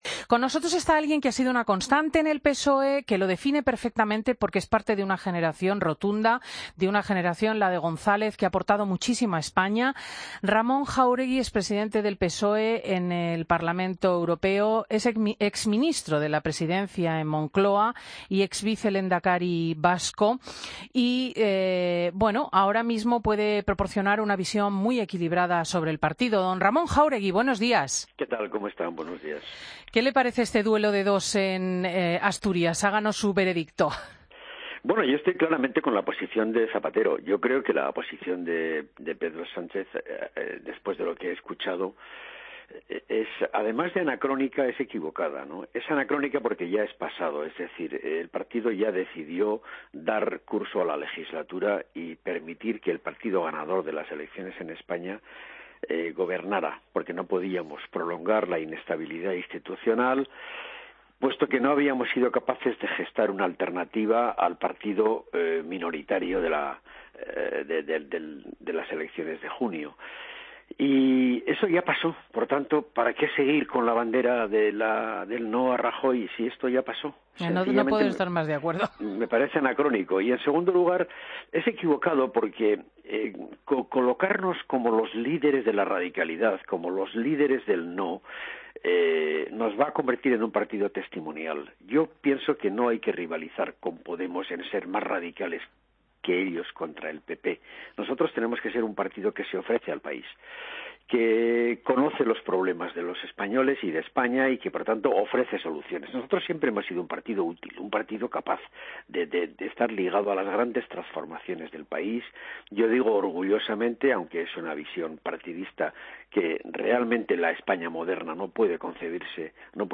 Escucha la entrevista a Ramón Jáuregui, portavoz del PSOE en el Parlamento Europeo, en Fin de Semana
Madrid - Publicado el 11 dic 2016, 10:47 - Actualizado 19 mar 2023, 04:42